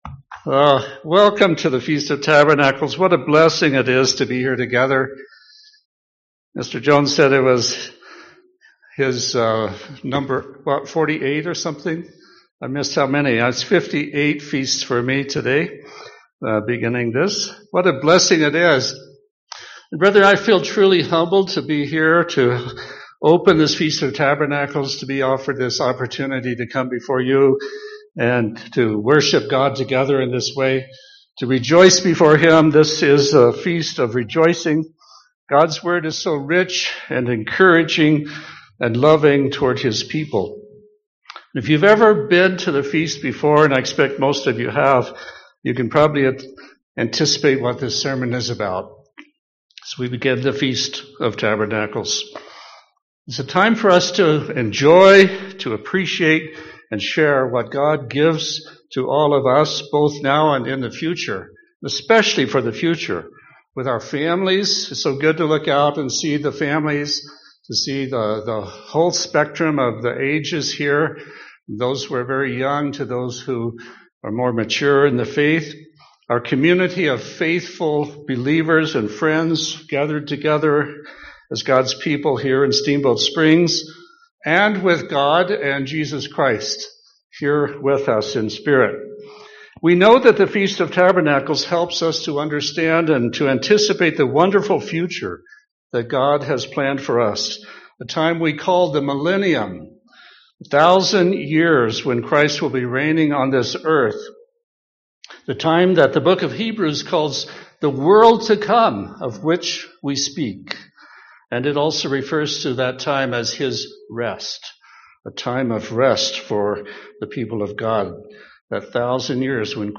This sermon was given at the Steamboat Springs, Colorado 2021 Feast site.